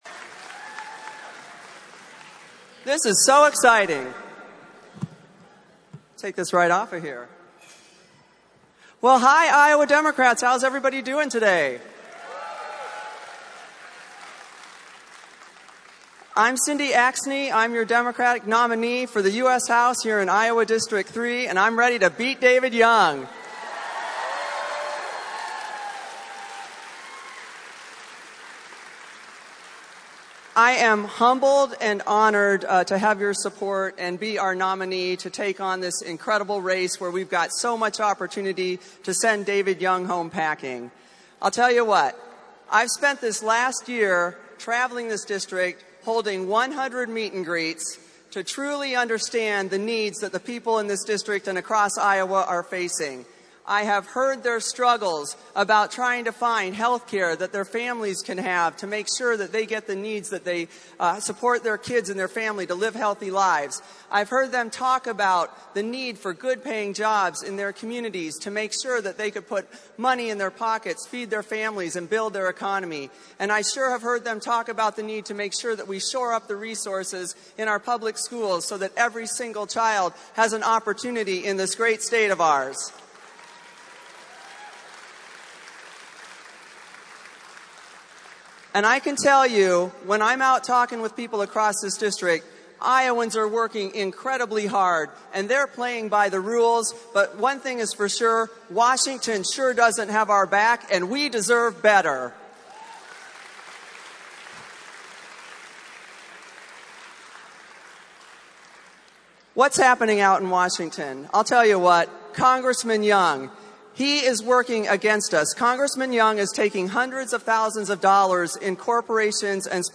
Cindy Axne of West Des Moines, Young’s Democratic challenger in the third congressional district, addressed delegates at her party’s state convention on Saturday as well.
AUDIO of Axne’s speech, 4:00